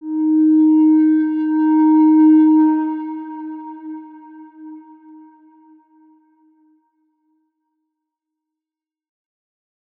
X_Windwistle-D#3-mf.wav